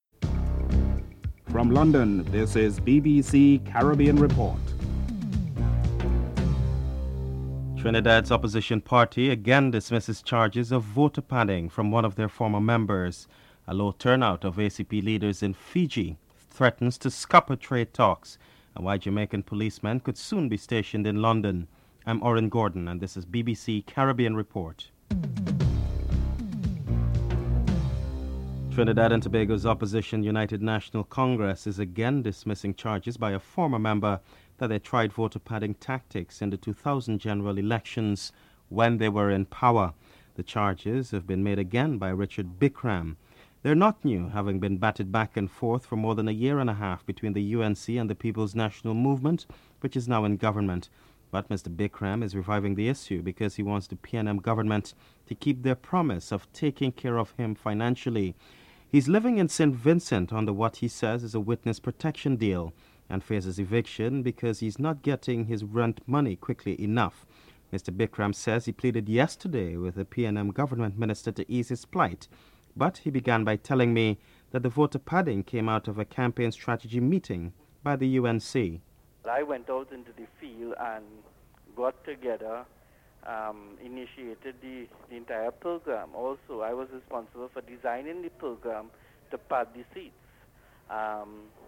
1. Headlines (00:00-00:26)